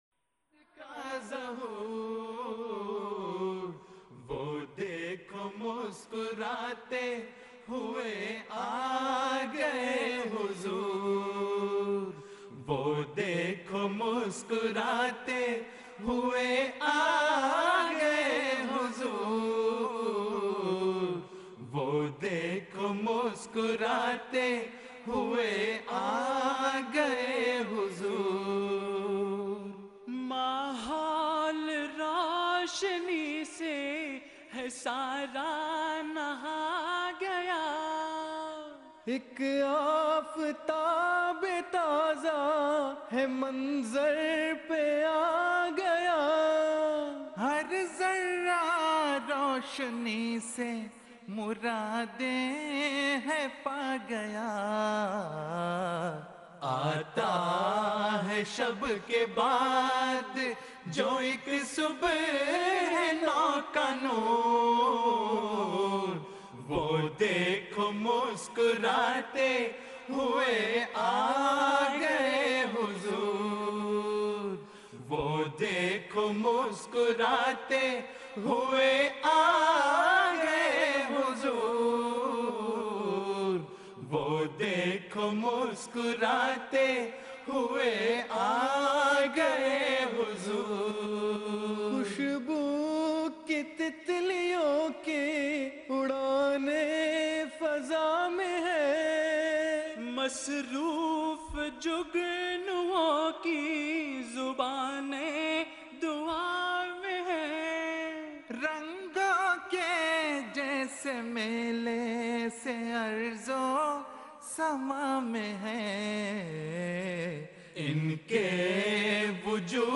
نظمیں (Urdu Poems)
آواز: خدام گروپ Voice: Group Khuddam